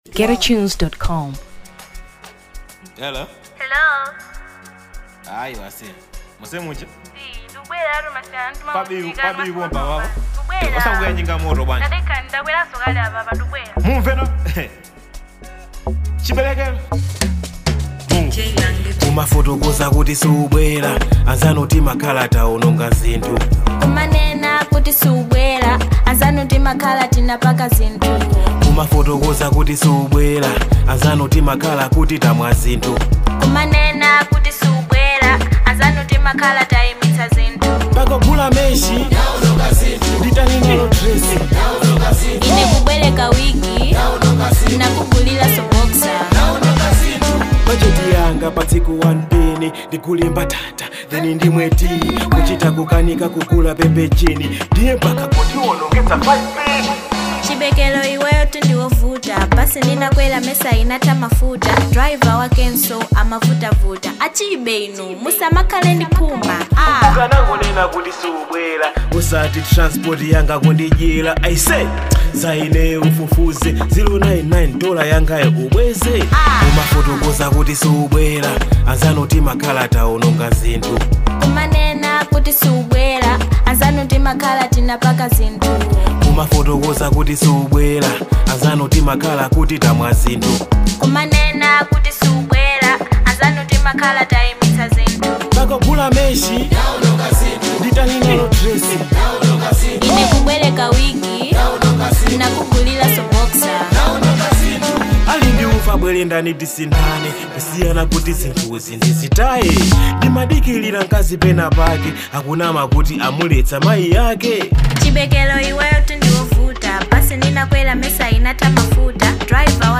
Afro Dancehall 2023 Malawi